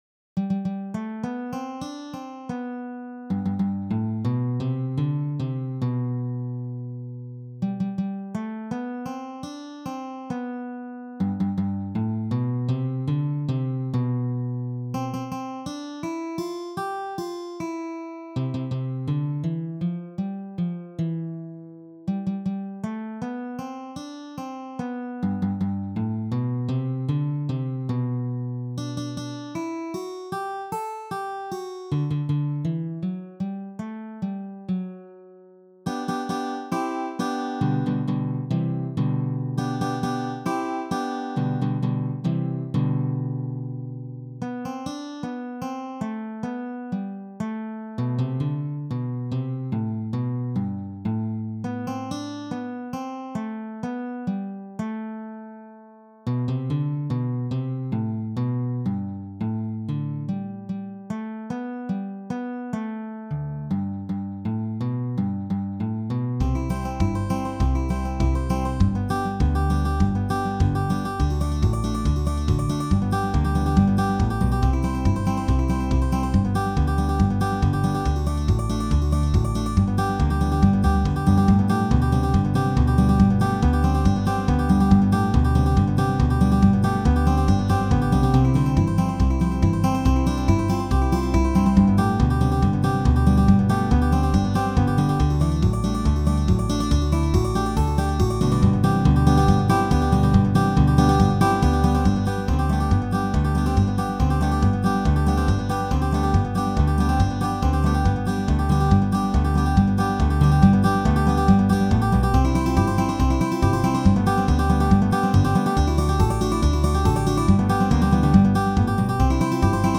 The last two sample file uploads are of 24-bit AIFF GarageBand archive exports to demonstrate their AV Foundation file compatibility.